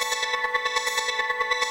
SaS_MovingPad04_140-A.wav